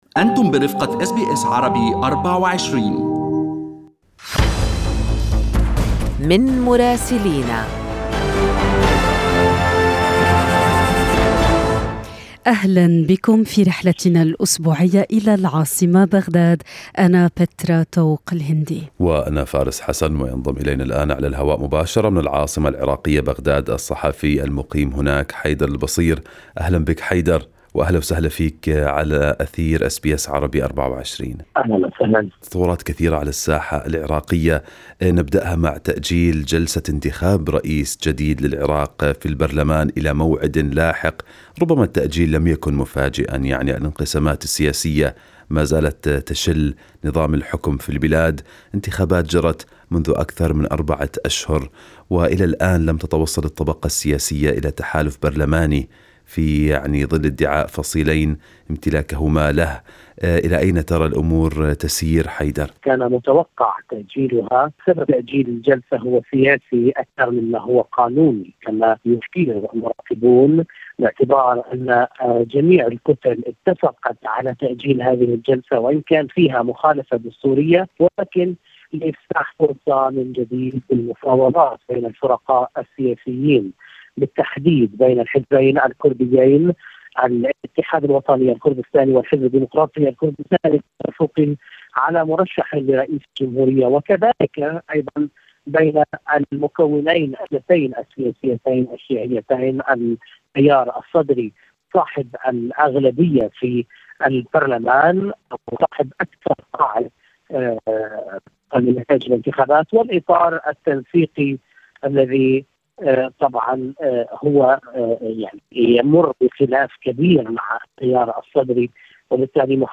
يمكنكم الاستماع إلى التقرير الصوتي من بغداد بالضغط على التسجيل الصوتي أعلاه.